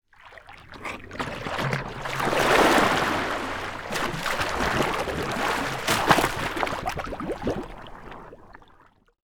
Water_44.wav